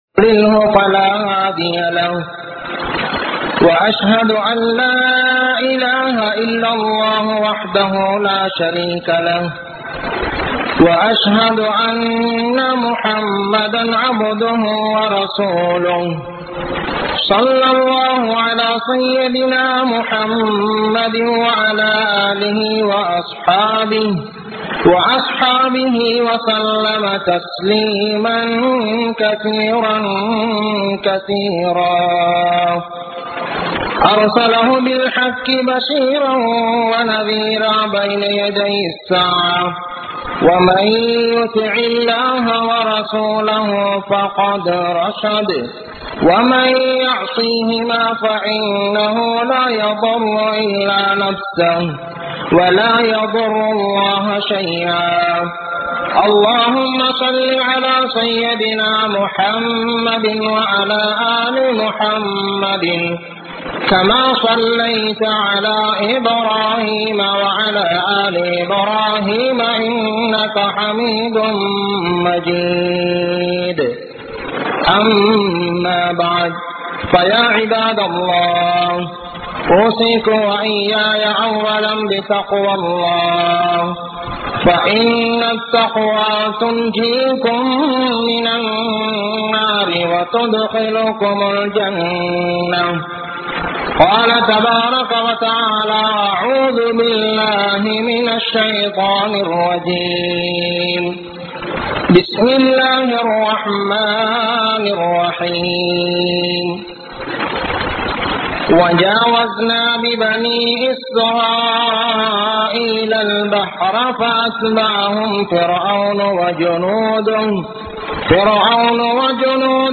Al Quranin Maatham (அல்குர்ஆனின் மாதம்) | Audio Bayans | All Ceylon Muslim Youth Community | Addalaichenai